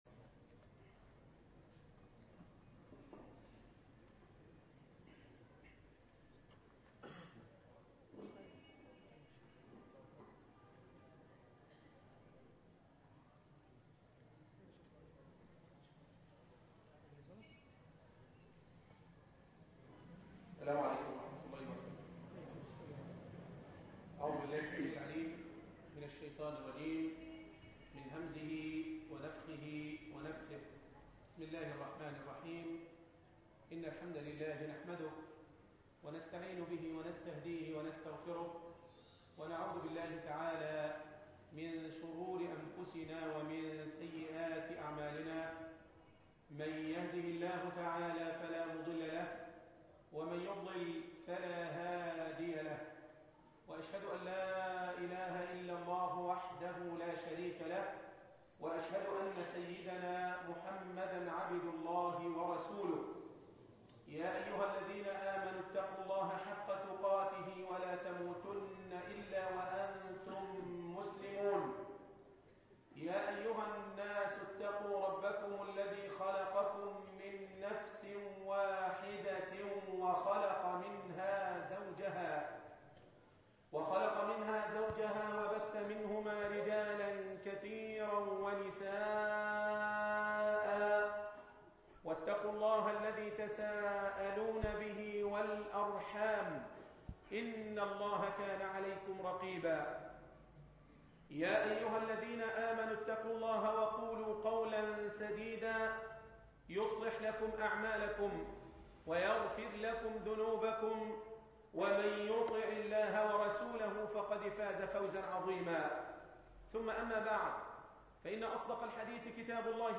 مسجد علي بن أبي طالب ـ شبرا الخيمة ـ القليوبية مؤتمر فبراير 2011 م